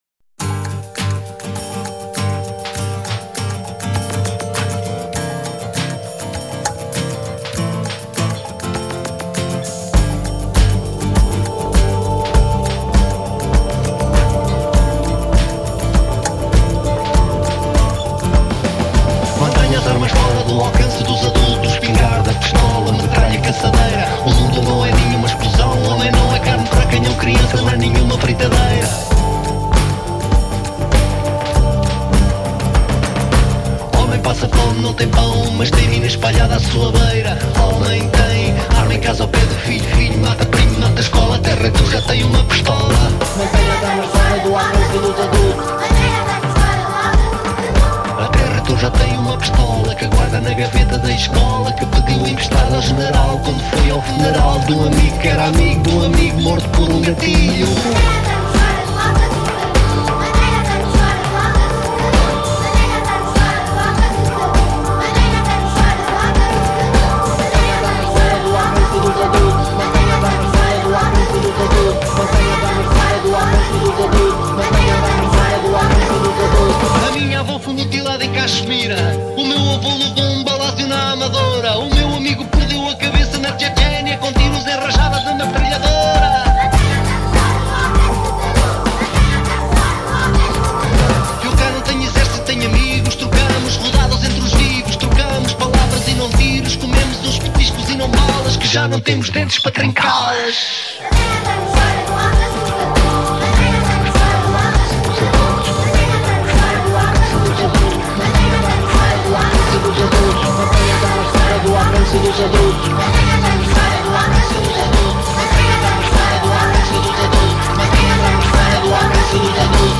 cantada também por um Coro Infantil
Agora em 2025, com nova masterização e mistura.